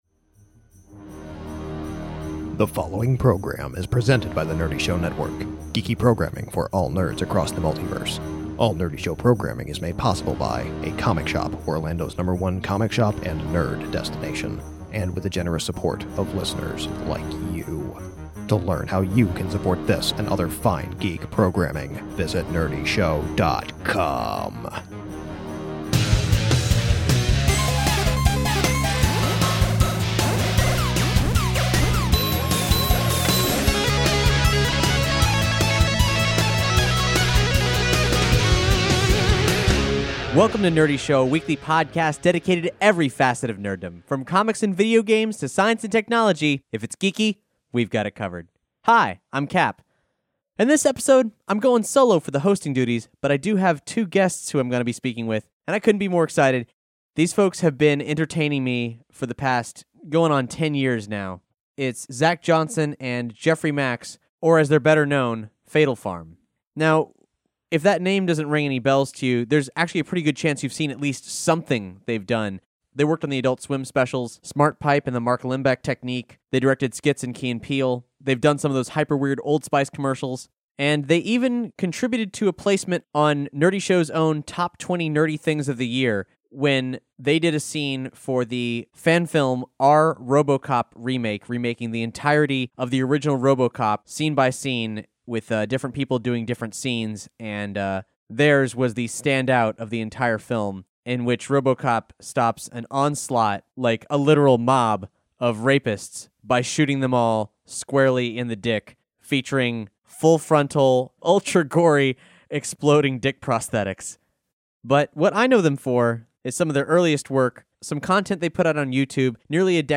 Nerdy Show Interview: Fatal Farm Returns to Lasagna Cat